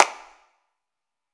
clap.wav